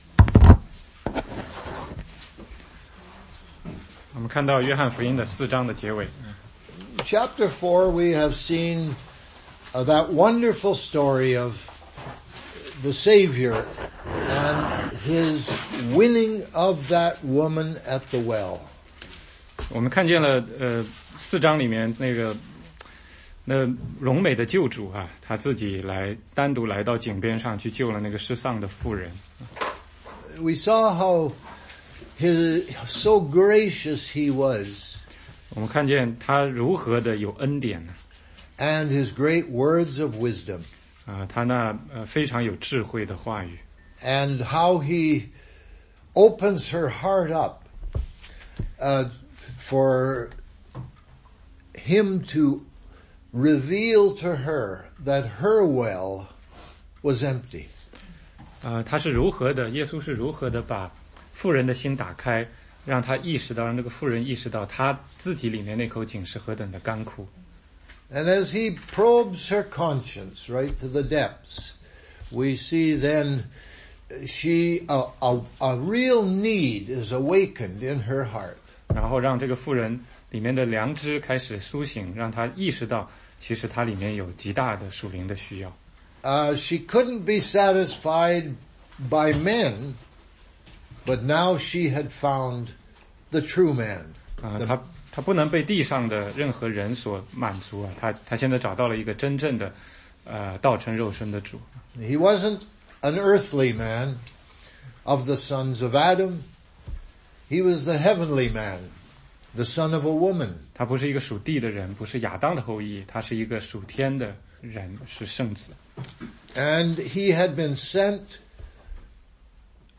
16街讲道录音 - 约翰福音4章43-54